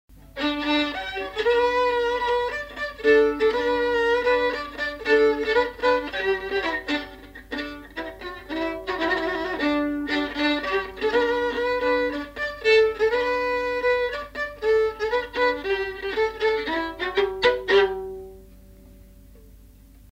Aire culturelle : Lomagne
Genre : morceau instrumental
Instrument de musique : violon
Danse : polka